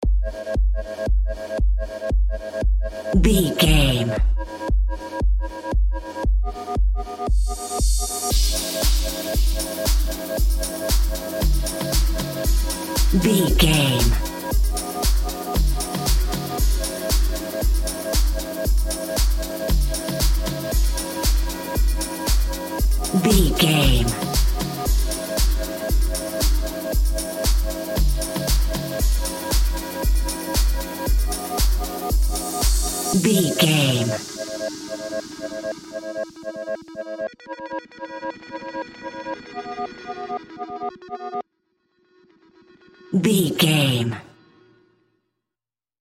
Aeolian/Minor
ethereal
dreamy
cheerful/happy
groovy
synthesiser
drum machine
house
electro dance
electronic
synth leads
synth bass
upbeat